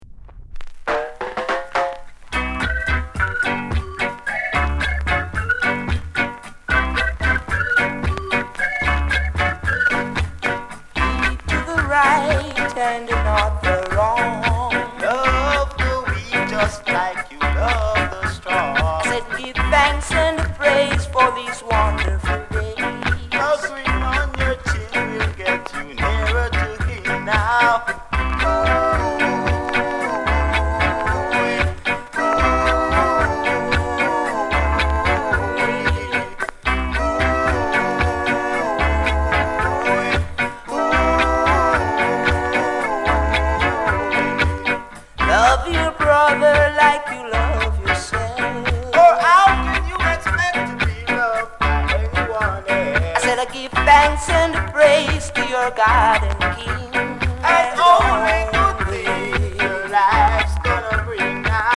Sound Condition A SIDE VG(OK)